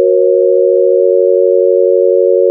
The first second of this WAV file contains a major triad starting on F# (F# - A# - C#) using the Just scale appropriate for C Major. The last part of the file contains the same triad but using the Just scale appropriate for F# Major.
Tuning Shift WAV file.